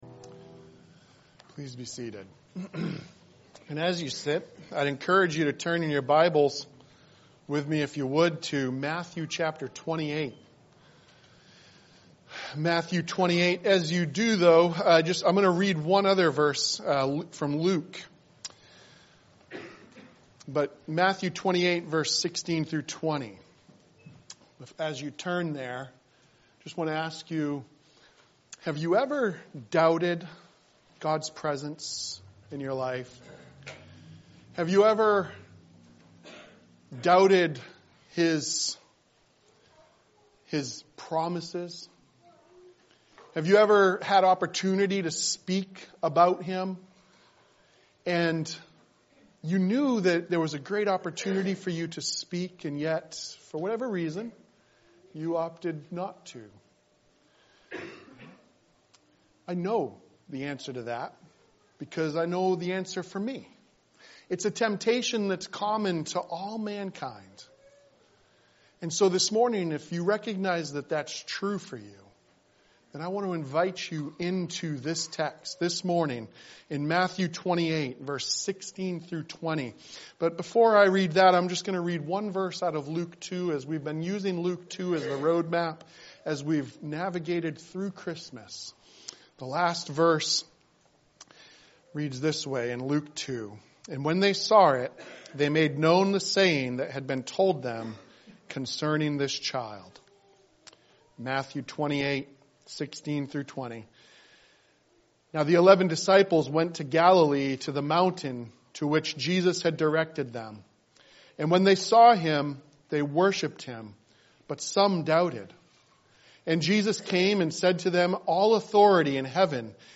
January 5, 2025 Songs of the Season series Advent 2024 Save/Download this sermon Luke 2:17 Other sermons from Luke 17 And when they saw it, they made known the saying that had been told them concerning this child.